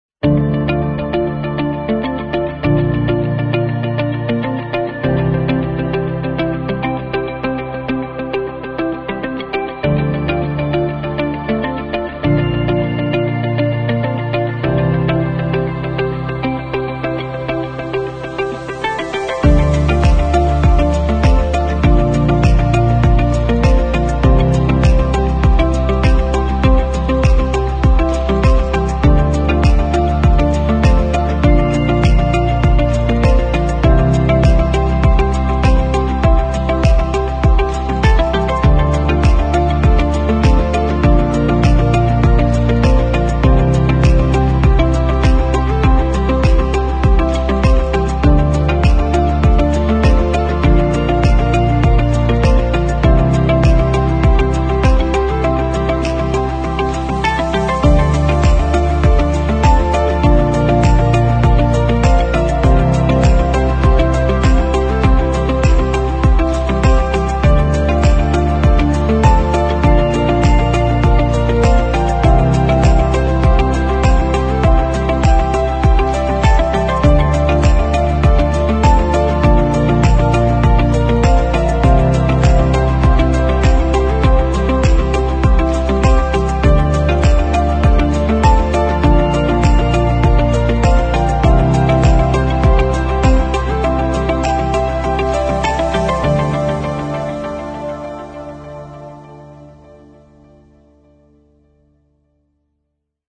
描述：极简主义，环境企业轨道。